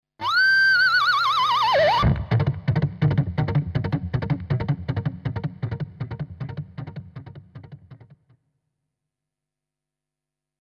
Cavallo   mp3 11'' 167 Kb
Cavallo.mp3